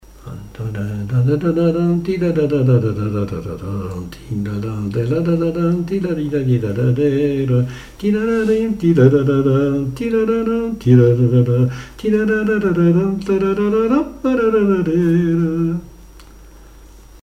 figure du quadrille des Lanciers
danse : quadrille : pastourelle
Pièce musicale inédite